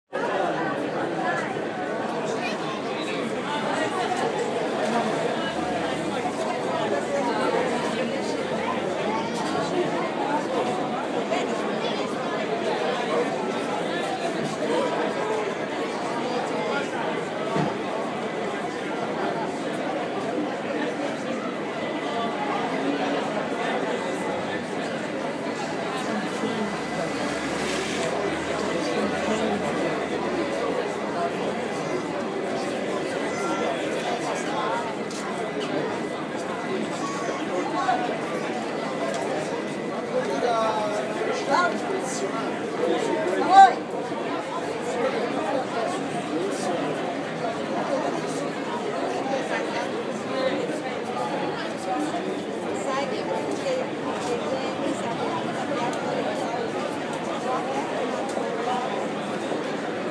Friday night on the West End in London
Standing at the Noel Coward Theatre waiting for Peter and Alice. The volume of people wandering around is overwhelming for a Nebraska girl on her own.